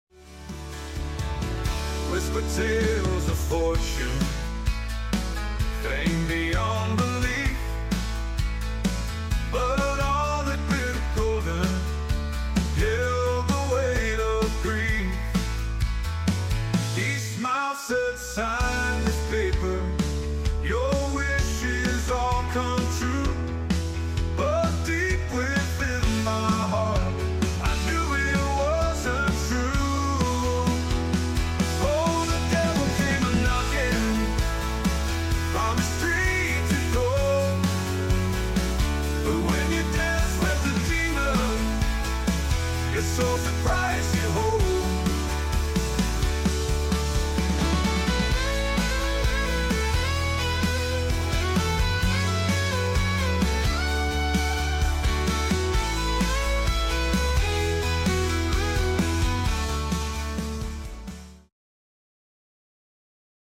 🎶💫 🎵 Remember to leave your comments below and let me know what you think of this AI-generated classic hit!